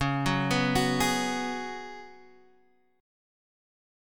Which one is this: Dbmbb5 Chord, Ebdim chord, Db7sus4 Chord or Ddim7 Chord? Db7sus4 Chord